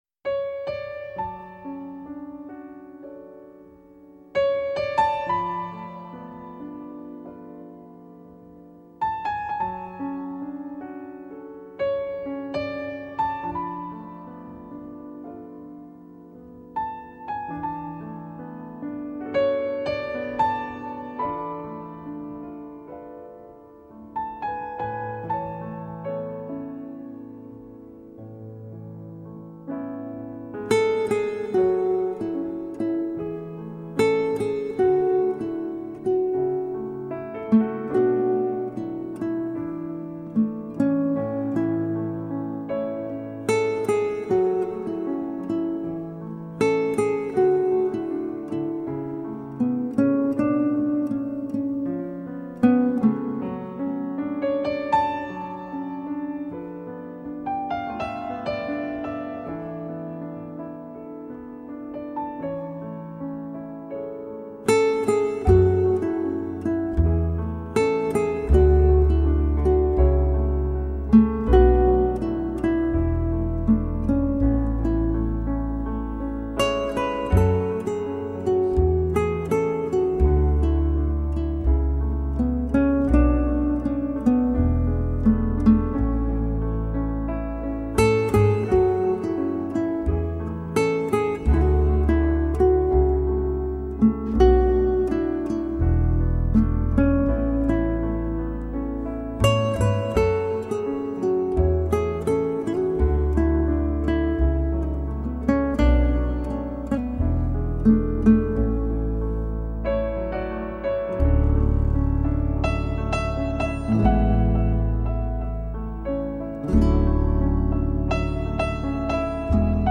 音乐风格：NewAge
轻柔的美声妙韻,配合水疗按摩,瑜珈冥想等活动,令心境平和宁静,满足自在,让身,心,灵得以洁净.